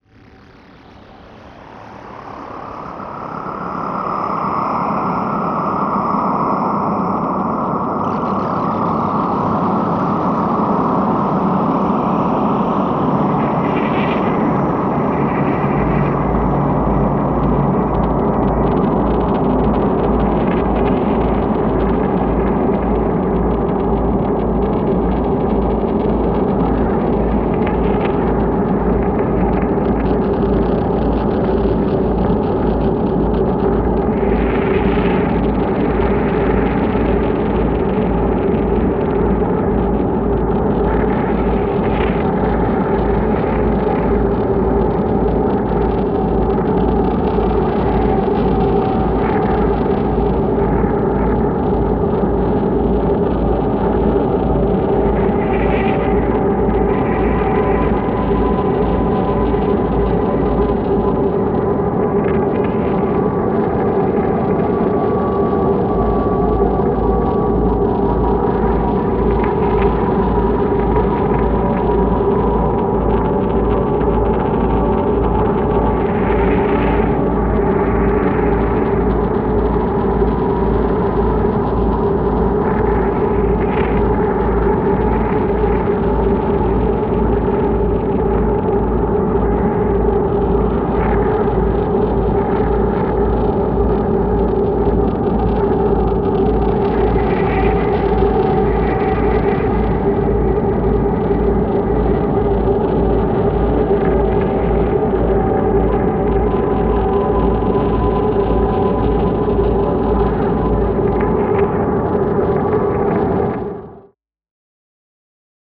free horror ambience 2
ha-crunchy_1.wav